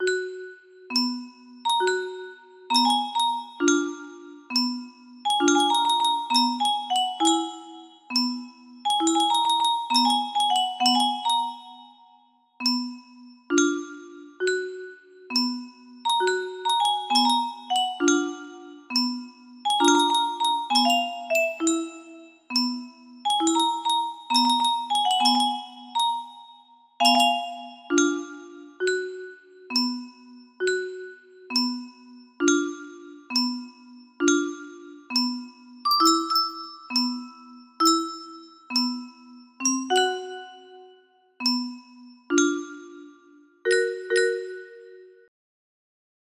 A clone version beta 30 note